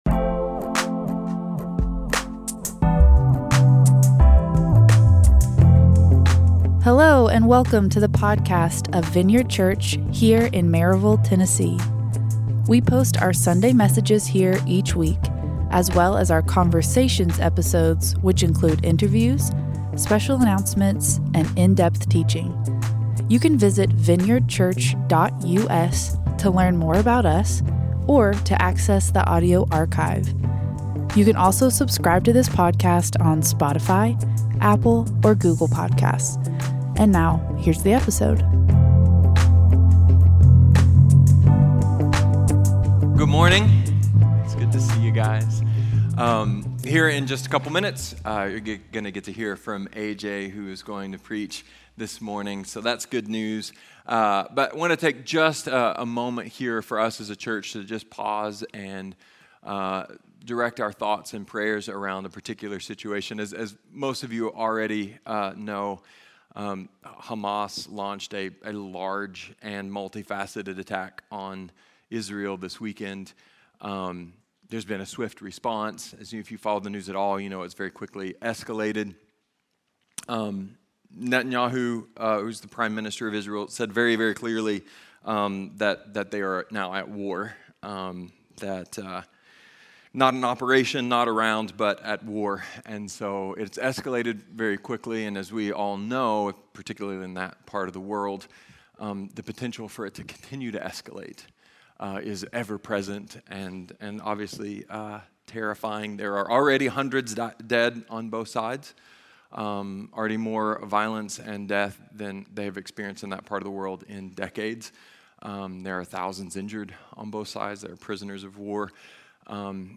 A message from the series "How God Sees You."